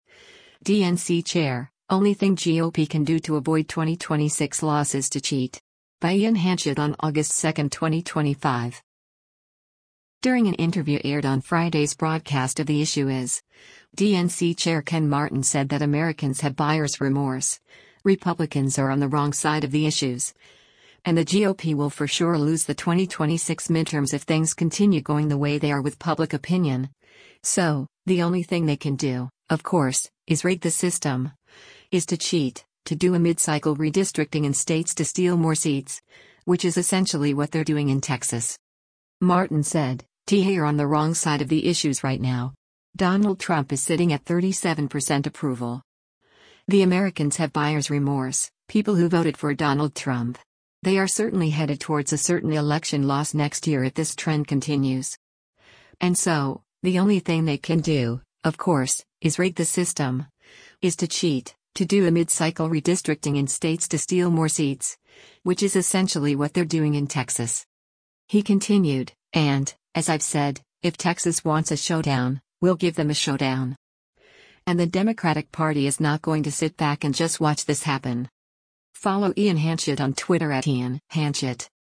During an interview aired on Friday’s broadcast of “The Issue Is,” DNC Chair Ken Martin said that “Americans have buyer’s remorse”, Republicans are “on the wrong side of the issues”, and the GOP will for sure lose the 2026 midterms if things continue going the way they are with public opinion, “so, the only thing they can do, of course, is rig the system, is to cheat, to do a mid-cycle redistricting in states to steal more seats, which is essentially what they’re doing in Texas.”